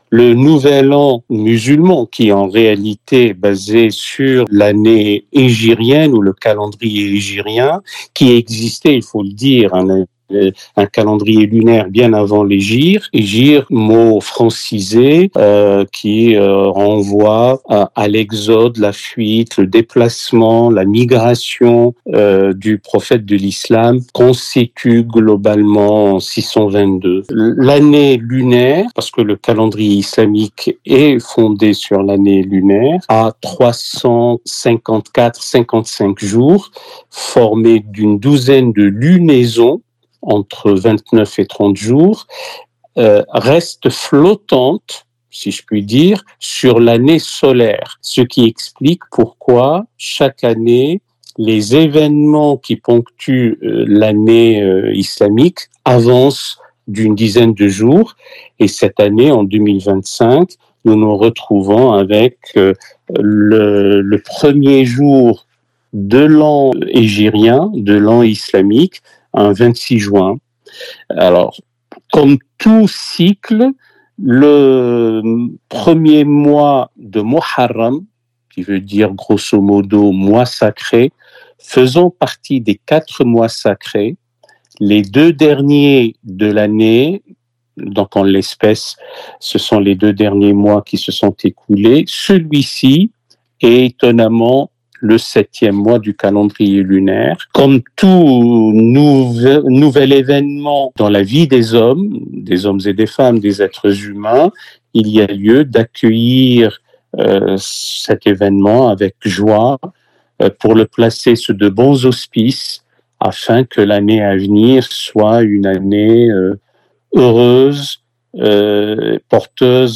Interview avec Ghaleb Bencheikh